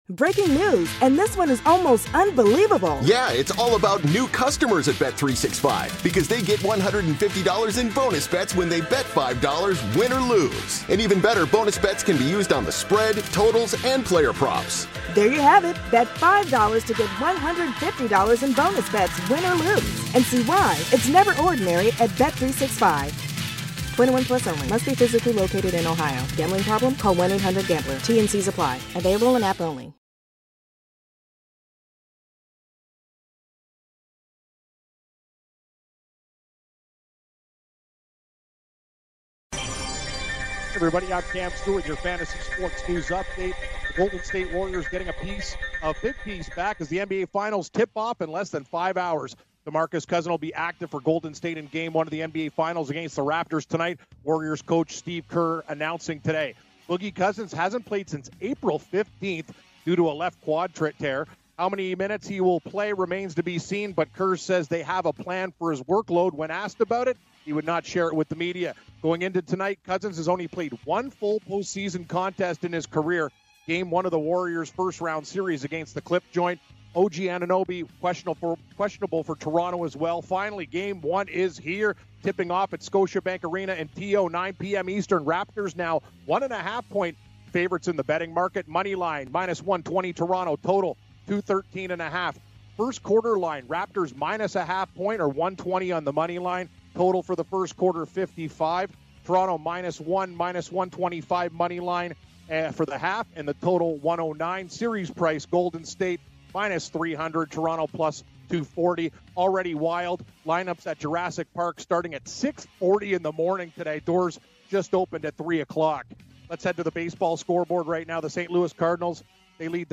Re-Air Rick Barry Interview